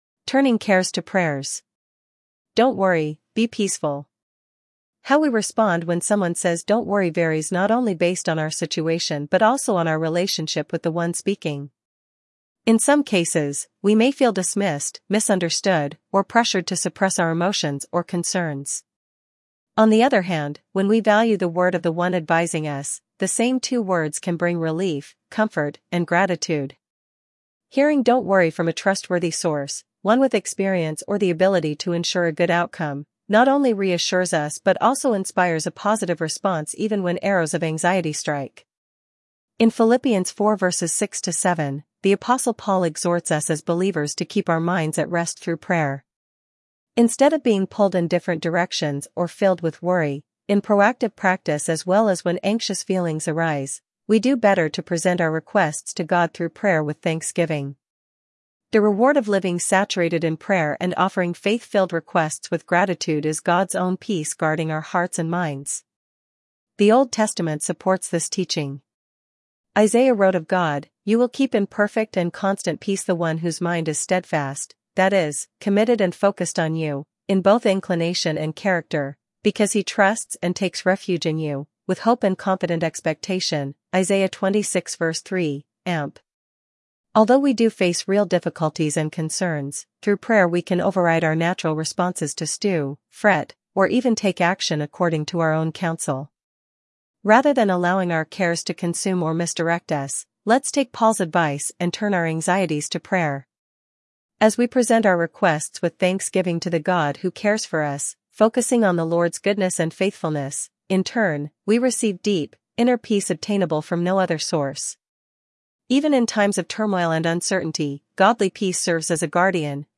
You can listen to the AI Audio Presentation and see the full Text/Words Information as well.